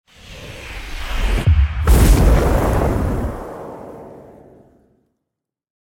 دانلود صدای آتش 15 از ساعد نیوز با لینک مستقیم و کیفیت بالا
جلوه های صوتی
برچسب: دانلود آهنگ های افکت صوتی طبیعت و محیط دانلود آلبوم صدای شعله های آتش از افکت صوتی طبیعت و محیط